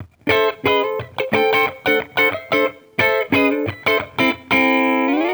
Index of /musicradar/sampled-funk-soul-samples/90bpm/Guitar
SSF_TeleGuitarProc1_90A.wav